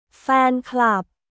ファーンクラップ